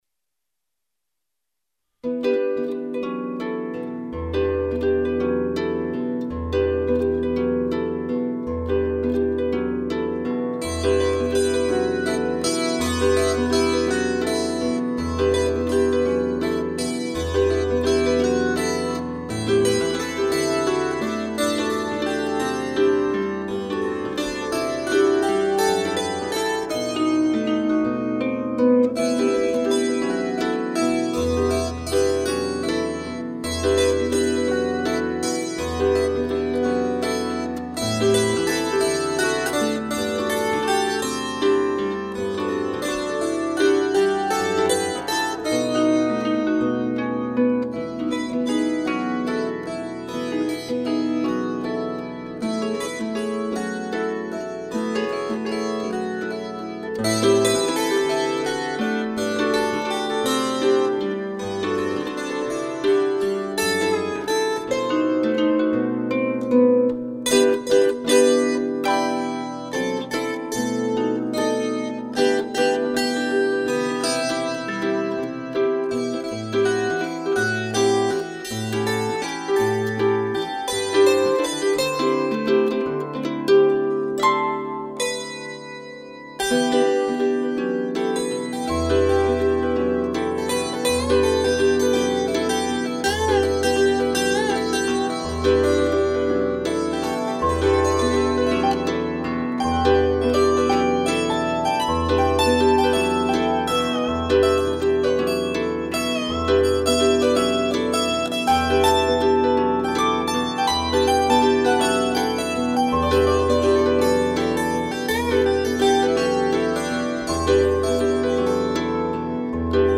cross-strung harp and vocal
wire-strung harp